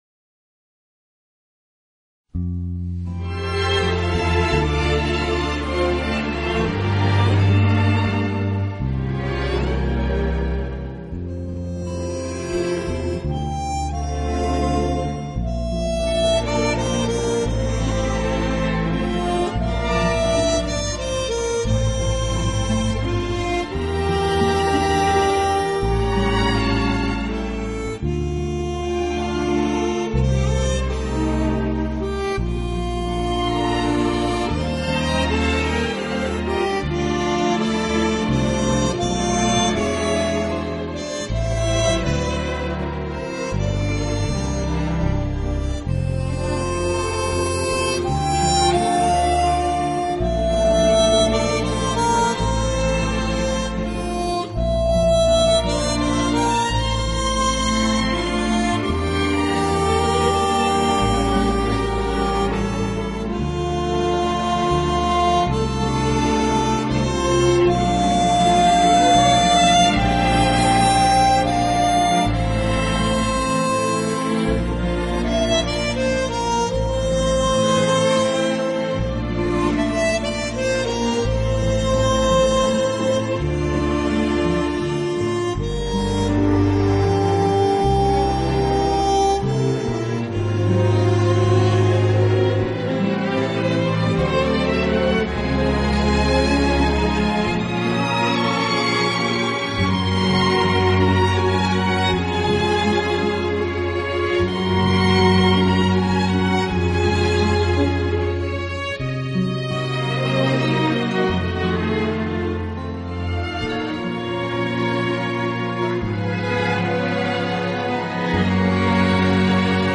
确的结像力，极宽的动态范围，更逼真的现场效果，更细腻纯正的音质，彻底超越传统CD
认为是简单的大众化乐器，但在有一定技术的演奏者手里也有相当丰富的表现力。
明快清新的演奏，脍炙人口的乐曲，一串串跳跃的音符随着口琴的吹奏变得更加生动。